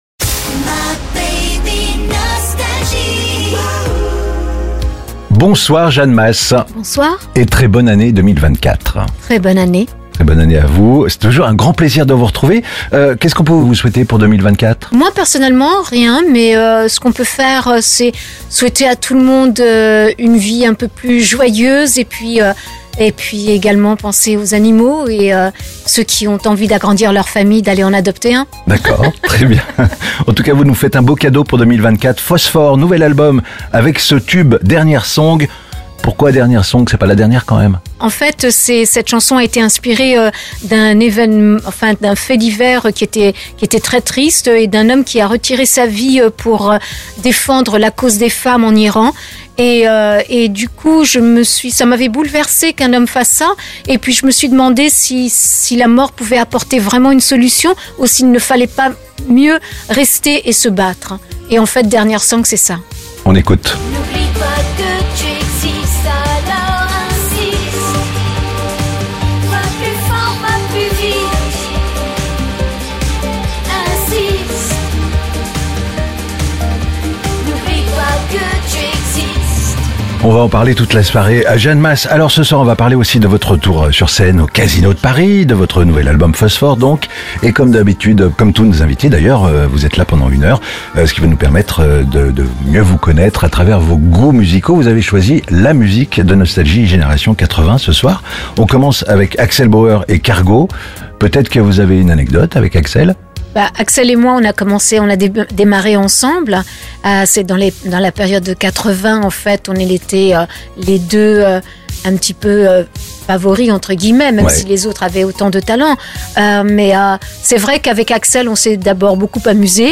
Mardi 23 janvier, Jeanne Mas était l'invitée exceptionnelle de Nostalgie Génération 80 pour présenter son actualité musicale et choisir la musique de Nostalgie